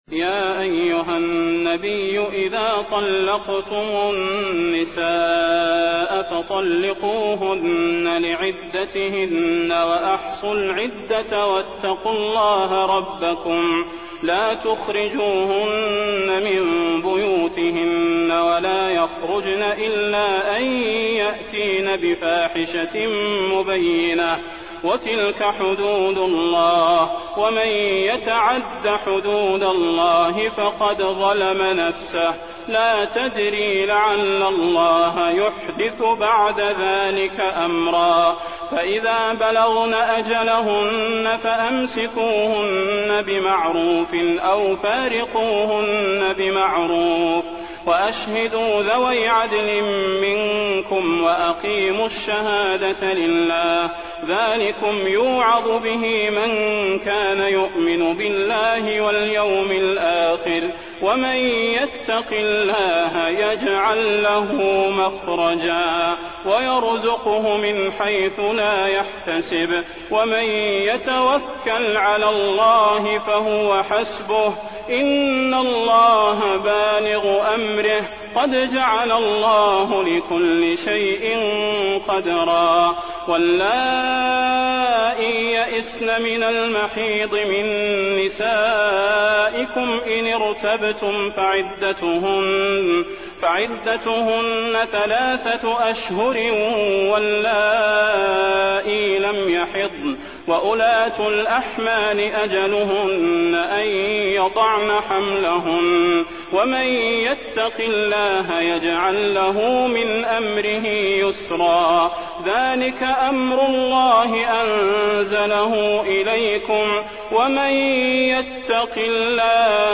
المكان: المسجد النبوي الطلاق